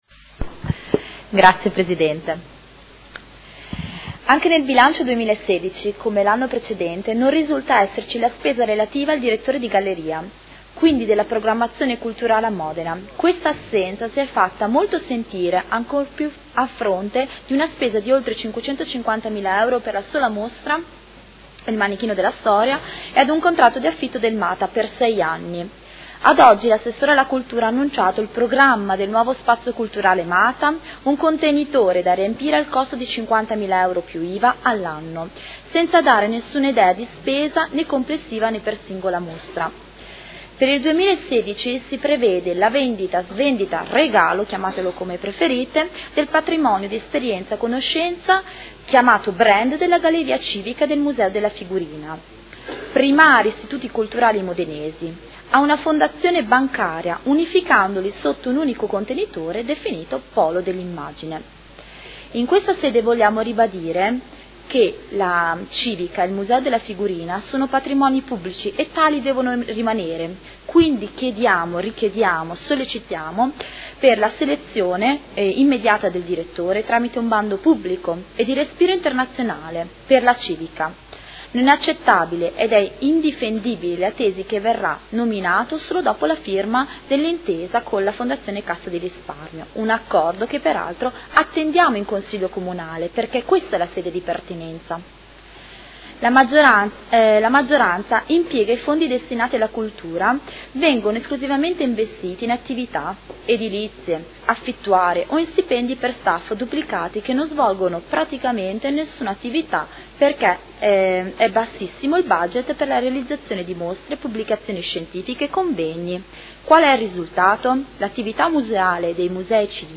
Seduta del 25 febbraio. Approvazione Bilancio: Dibattito